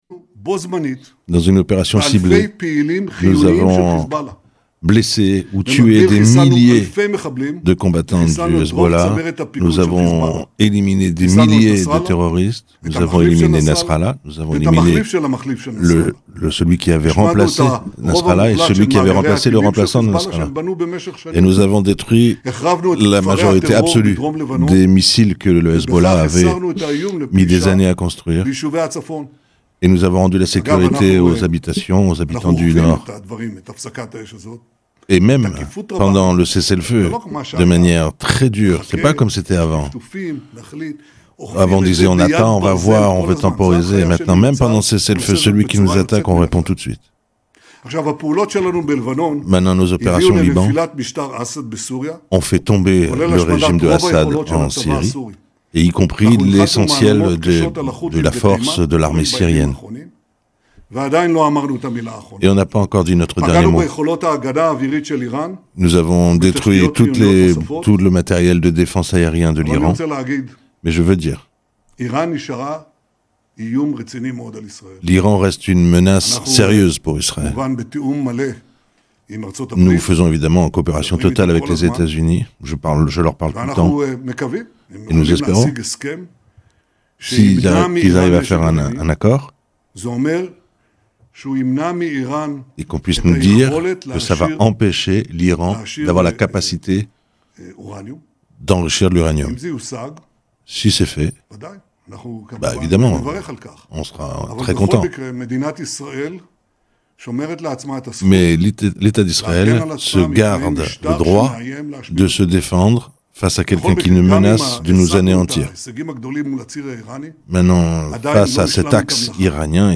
Ecoutez le discours de Netanyahou devant l'Assemblée Générale de l'Onu traduit en français